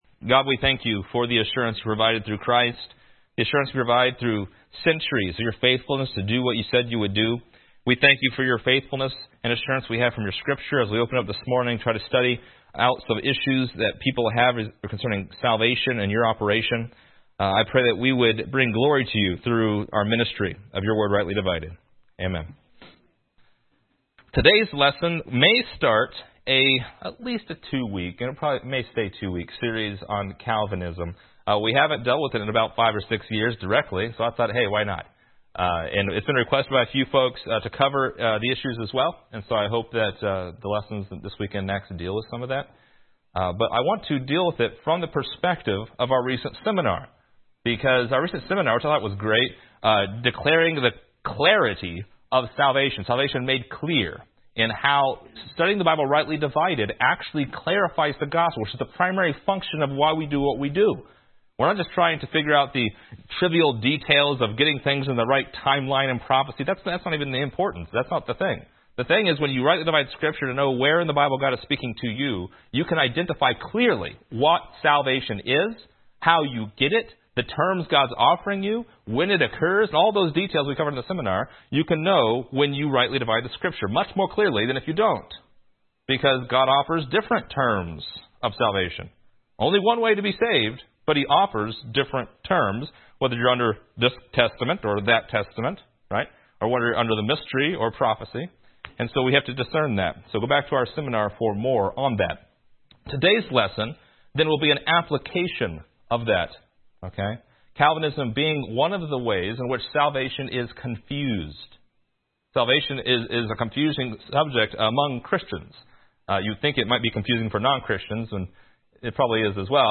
Download MP3 | Download Outline Description: The Bible speaks about predestination, election, grace, and salvation. However, Calvinism brings confusion to the terms of salvation and when salvation is delivered. See our most recent seminar’s lessons applied in this lesson titled, “Calvinism’s Confusion.”